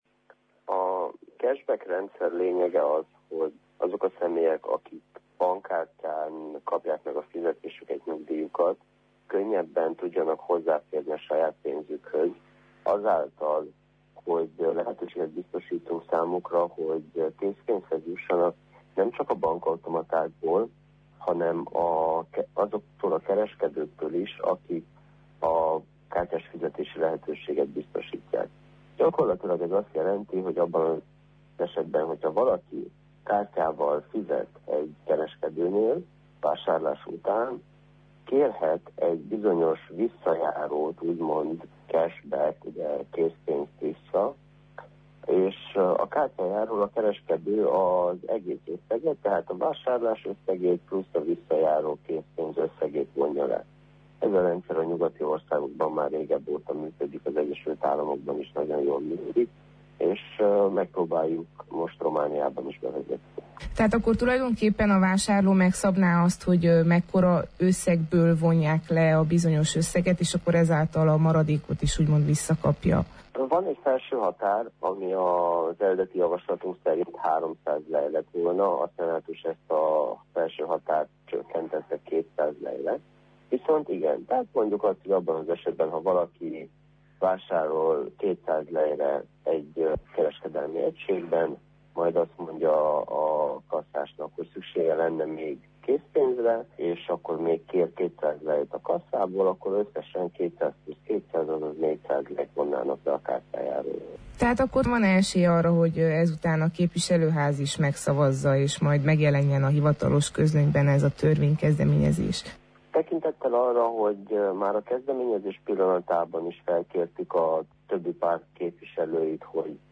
A tervezet kidolgozóját, a Szövetség parlamenti képviselőjét, Molnár Zsoltot kérdeztük a törvénykezdeményezés részleteiről.